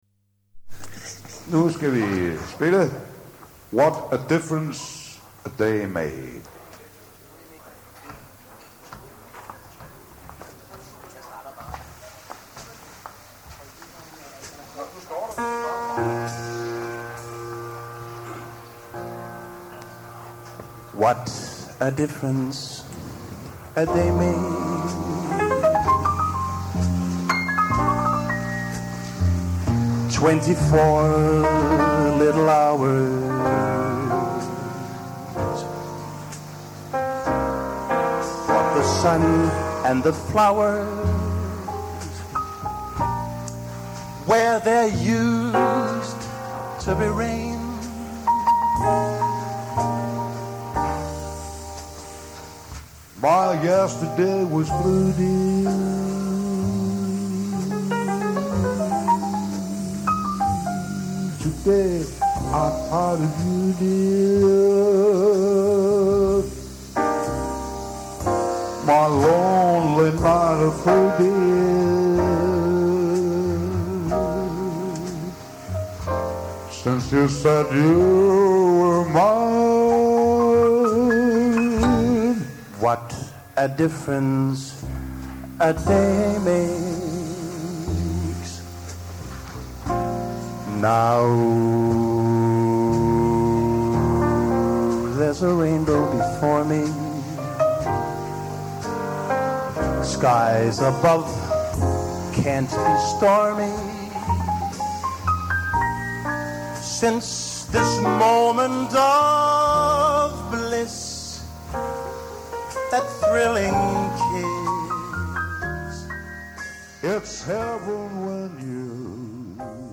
klaver
Der er ro på.